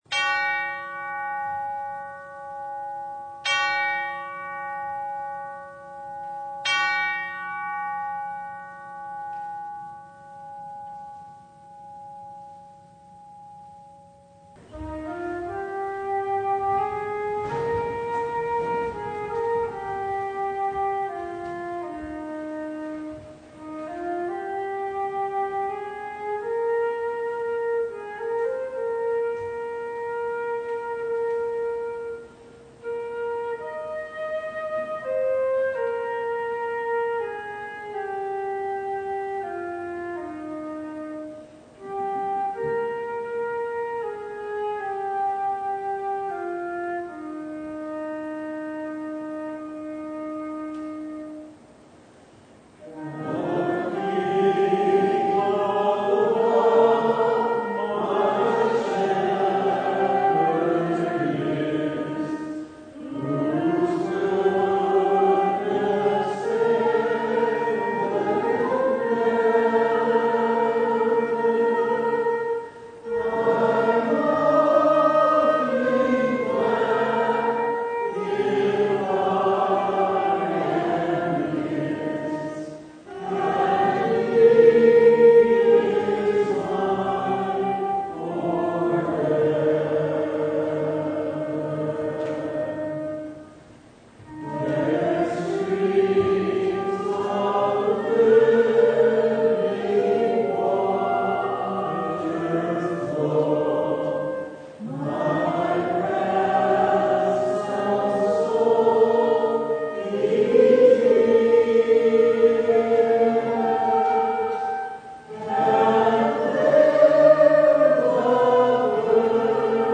Passage: Matthew 14:13-21 Service Type: Sunday
Topics: Full Service